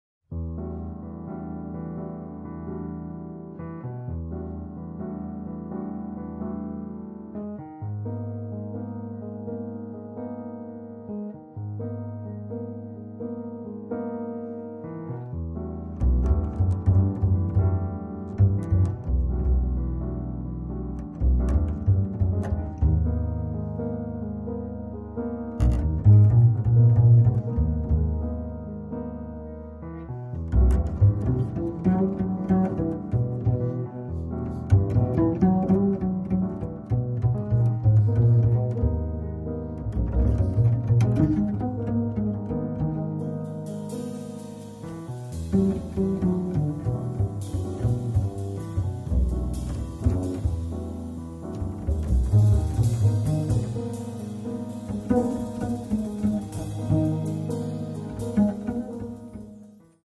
sax soprano, tenore, clarinetto
pianoforte
contrabbasso
batteria
si muovono all'interno della tradizione jazzistica europea